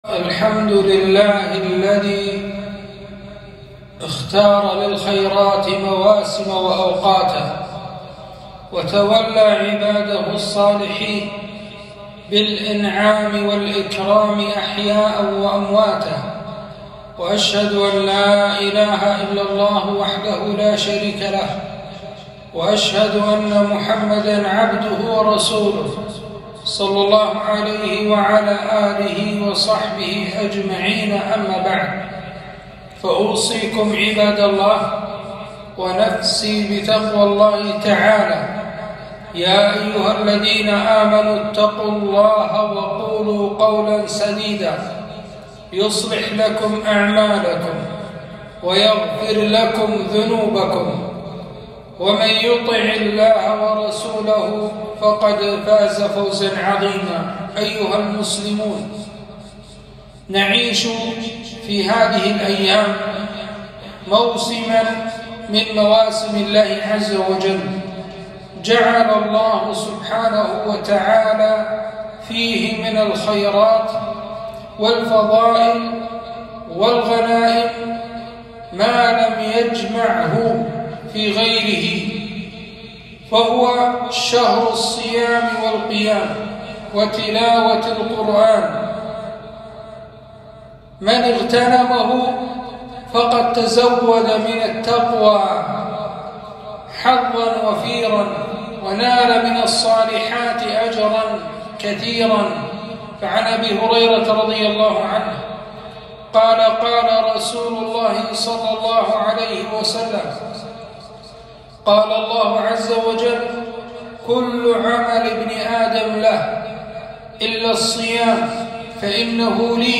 خطبة - وانتصف رمضان